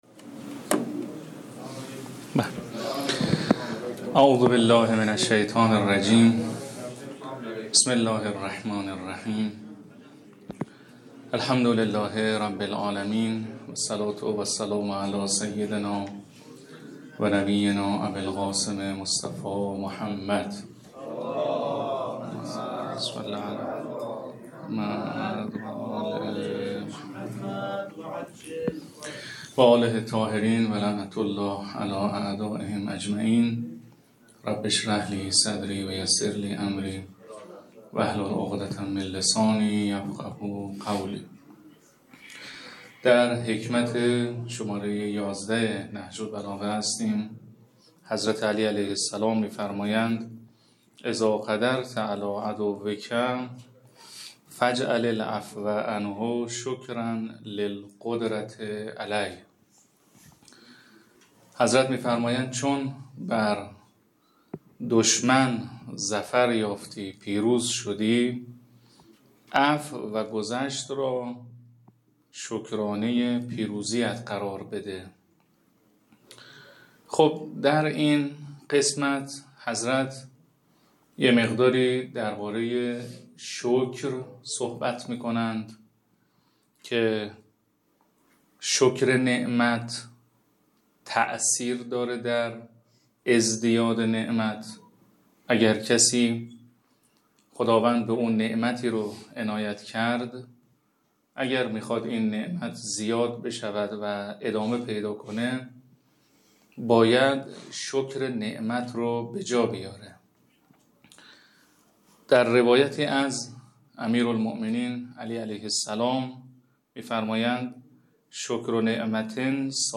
برگزاری جلسه شرح حکمت‌های نهج‌البلاغه در کرمان+صوت
حوزه/بیست‌ و نهمین جلسه از سلسله جلسات شرح حکمت‌های نهج‌البلاغه با حضور طلاب مدرسه علمیه امام مهدی عج و مؤسسه امام صادق علیه‌السلام برگزار شد.
در ادامه، پرسش‌های طلاب درباره مفاهیم حکمت‌های خوانده‌شده مطرح شد و توضیحات لازم ارائه گردید.